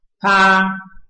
臺灣客語拼音學習網-客語聽讀拼-海陸腔-單韻母
拼音查詢：【海陸腔】pa ~請點選不同聲調拼音聽聽看!(例字漢字部分屬參考性質)